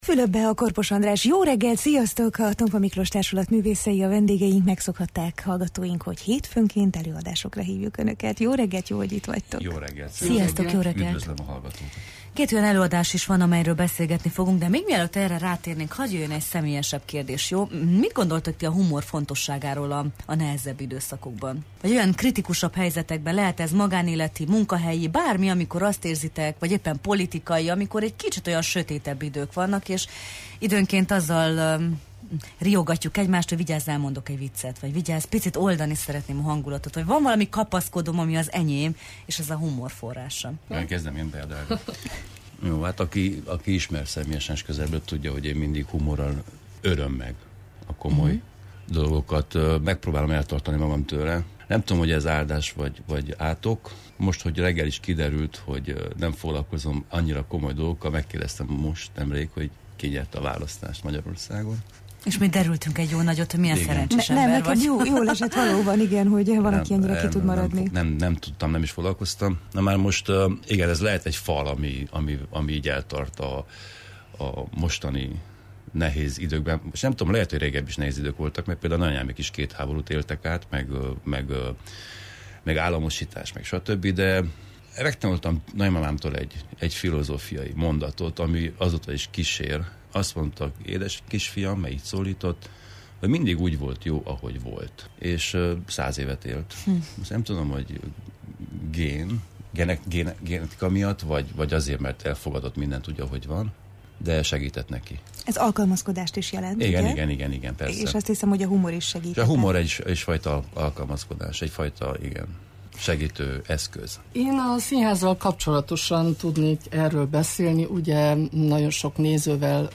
A beszélgetés során a produkciók aktualitásáról és a próbafolyamatokról is szó esett. https